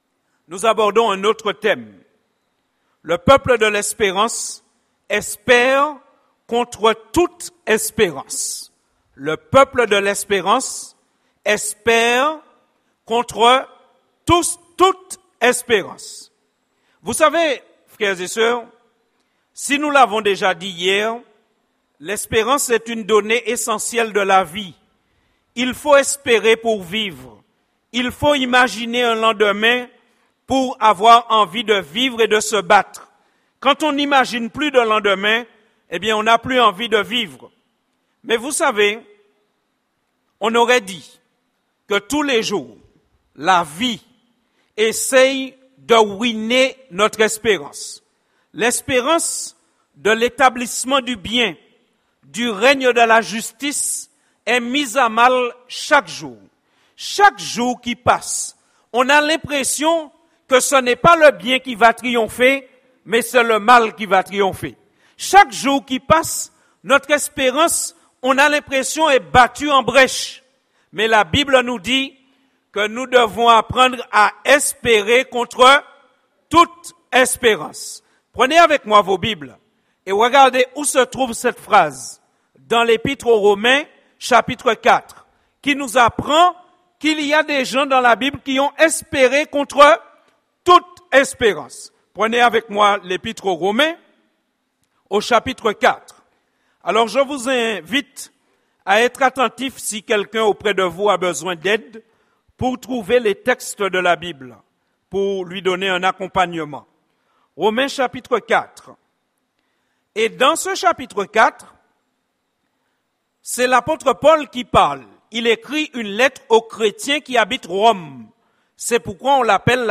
Sermons Semaine de prière